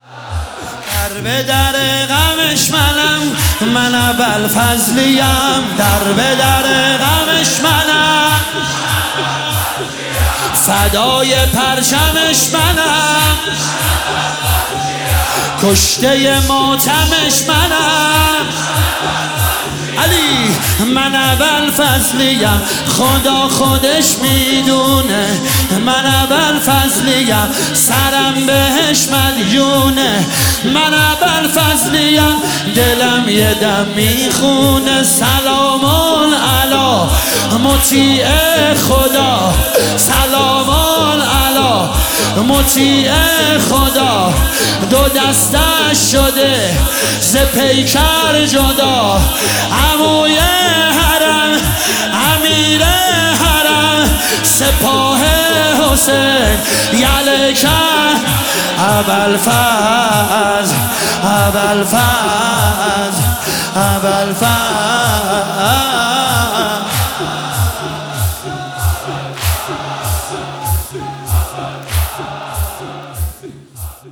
دانلود مداحی واحد